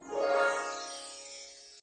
magic_harp_2.ogg